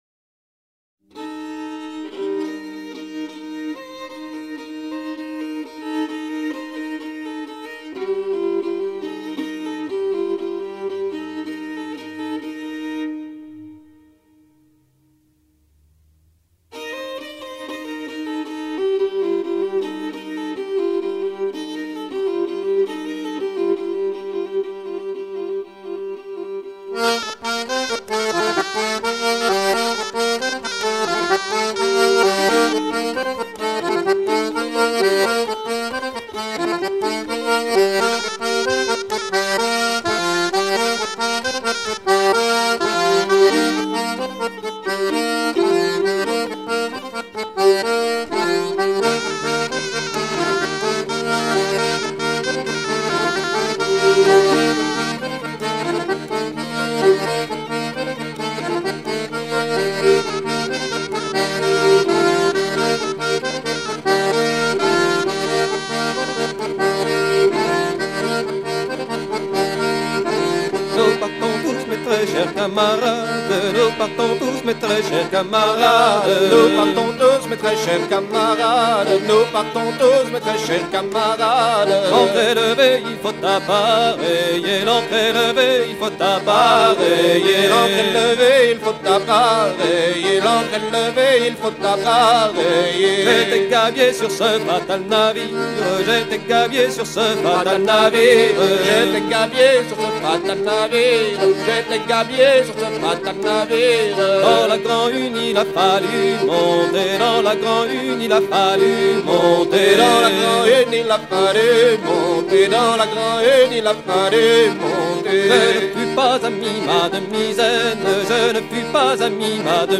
danse : ronde
Pièce musicale éditée